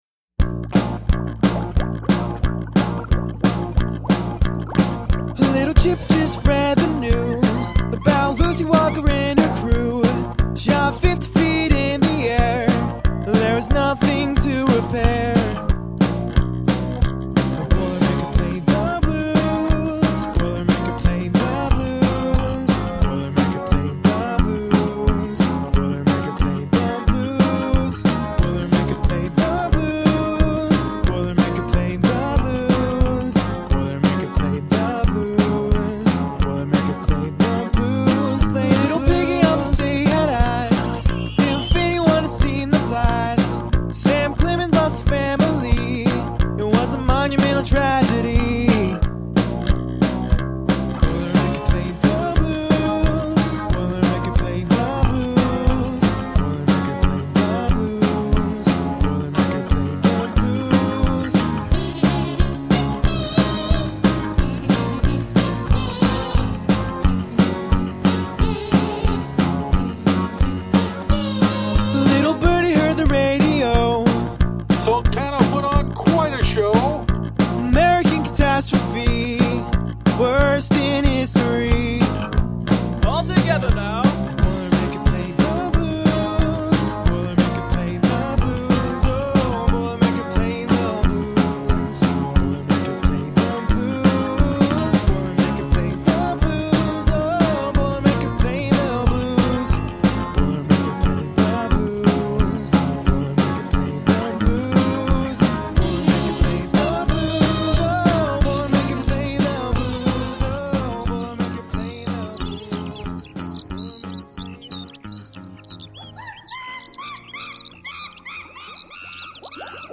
Kazoos imitate bees in the song structure.)